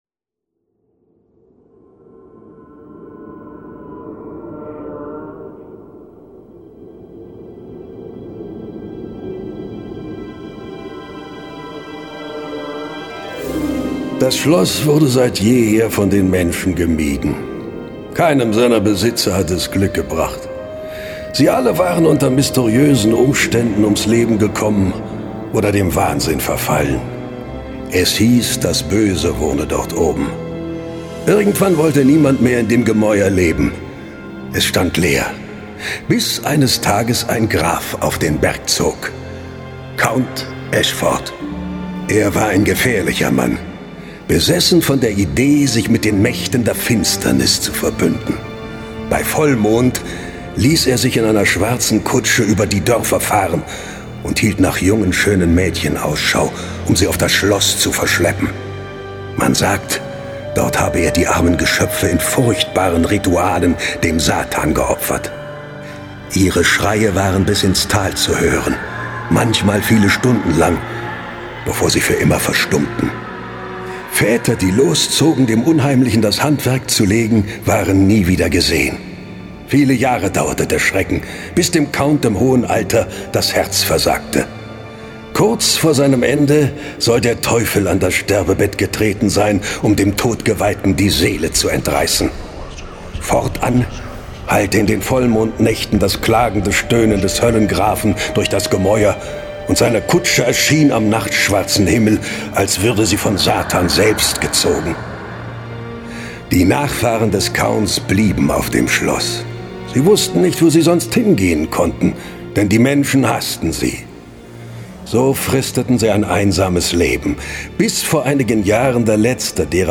John Sinclair - Folge 21 Die Höllenkutsche. Hörspiel.